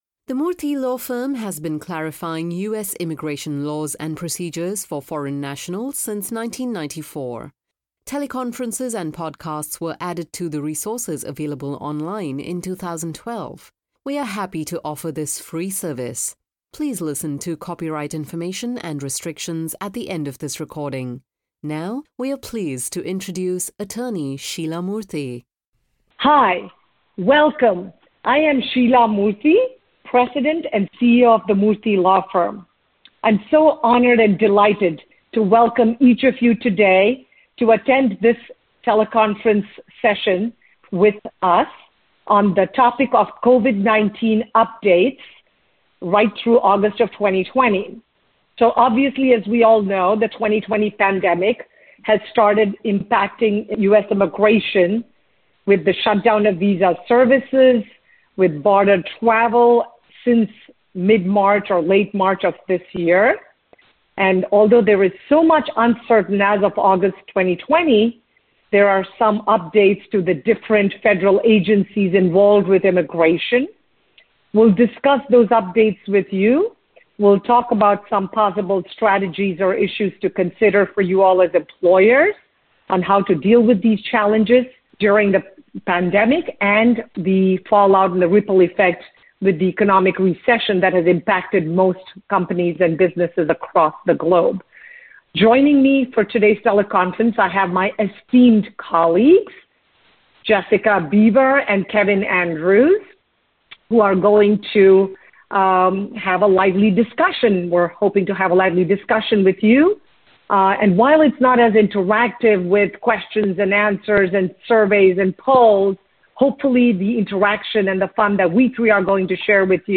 Murthy Law Firm attorneys provide an update on how COVID-19 has affected U.S. immigration both in the U.S. and at U.S. consulates, which aired on 05.Aug.2020 as part of our teleconference series for employers and their representatives.